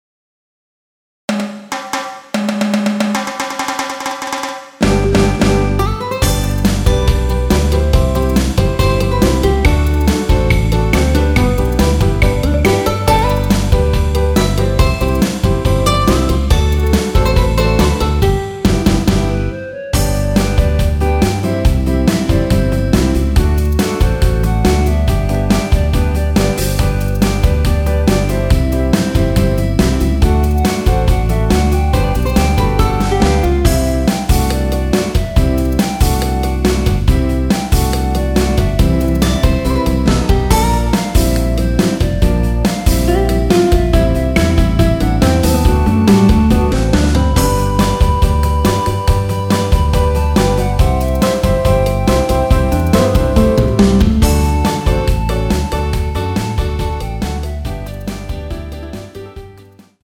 원키에서(-1)내린 멜로디 포함된 MR입니다.
멜로디 MR이라고 합니다.
앞부분30초, 뒷부분30초씩 편집해서 올려 드리고 있습니다.
중간에 음이 끈어지고 다시 나오는 이유는